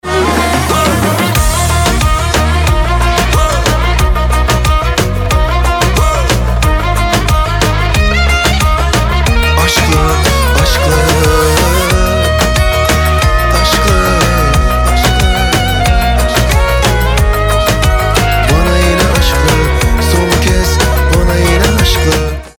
• Качество: 320, Stereo
красивые
восточные
арабские